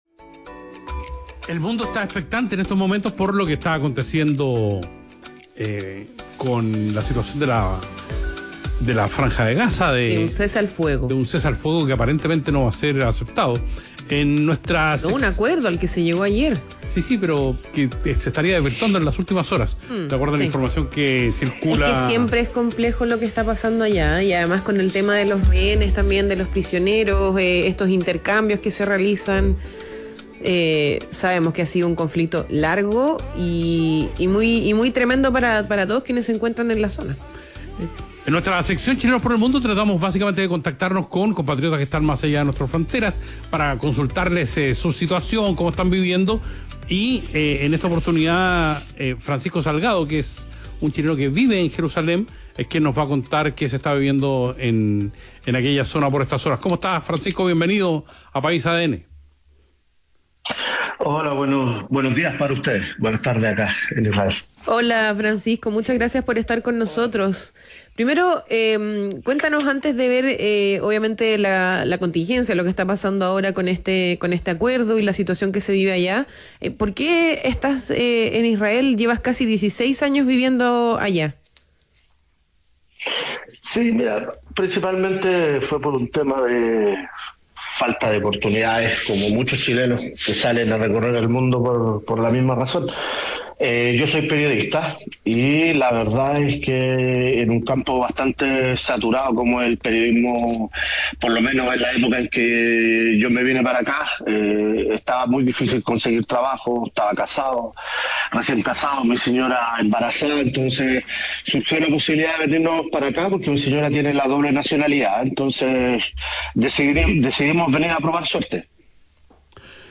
En conversación con País ADN